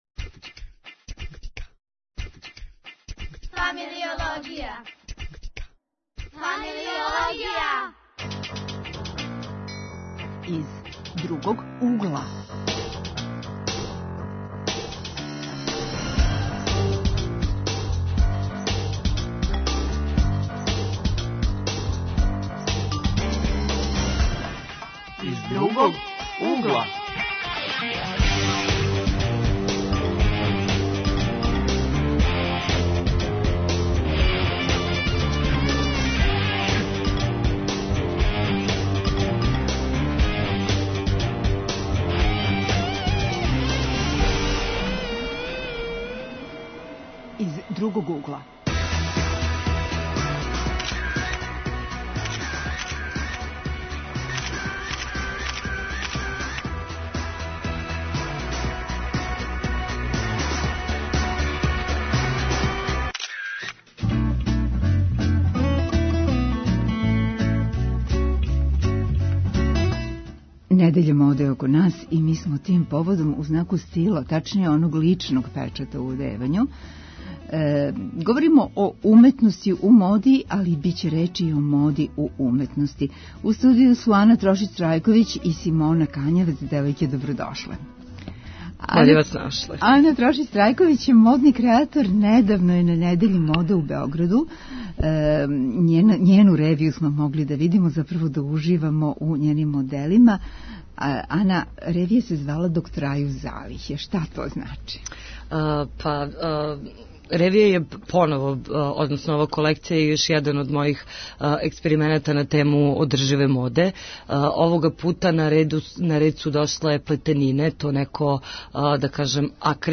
Гости су студенти